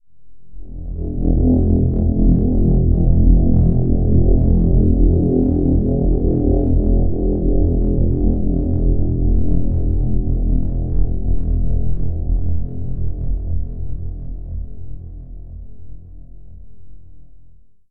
ambience brown brown-noise dark deep drone intro noise sound effect free sound royalty free Memes